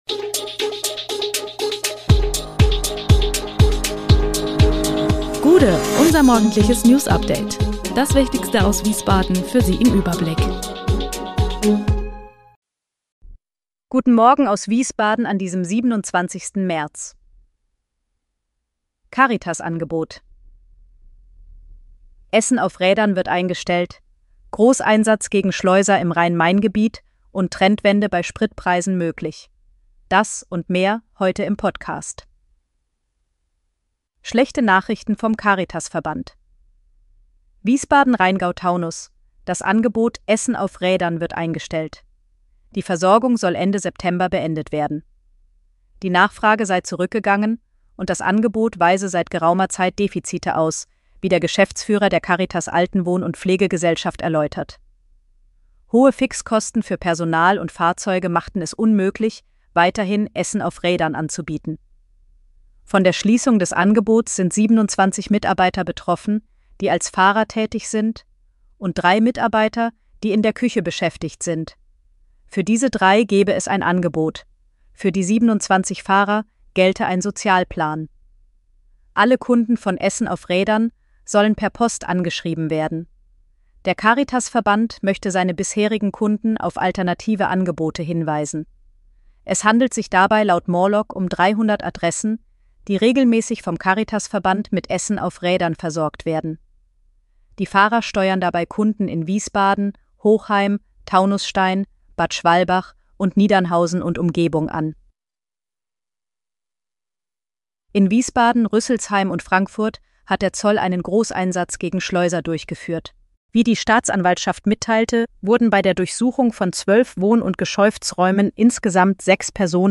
Der Podcast am Morgen für die Region
Nachrichten